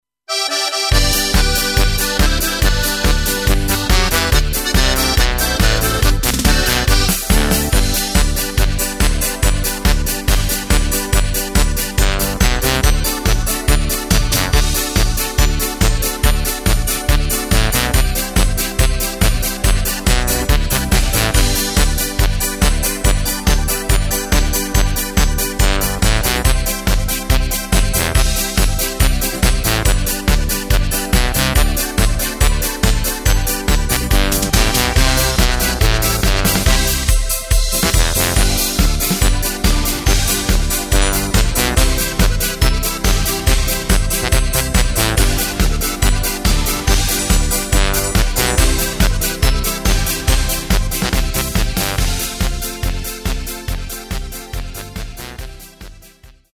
Takt:          2/4
Tempo:         141.00
Tonart:            G
Playback mp3 Mit Drums